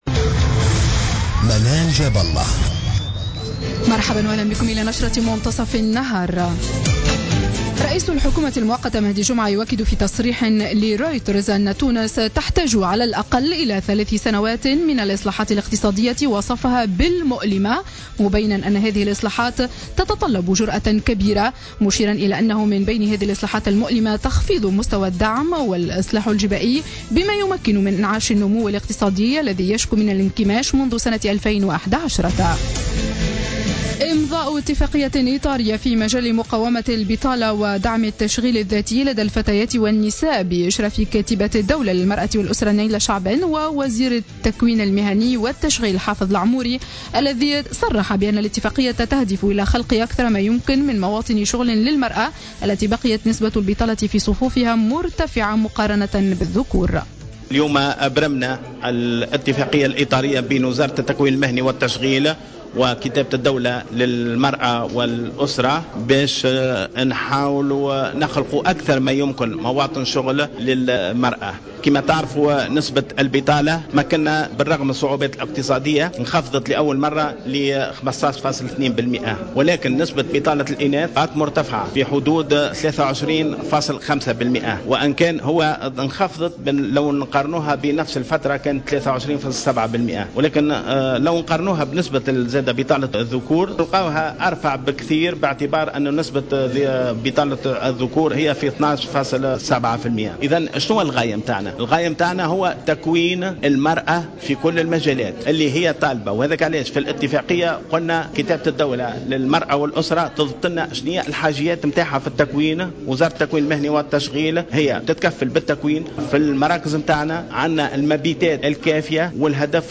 نشرة أخبار منتصف النهار ليوم الإثنين 13-10-14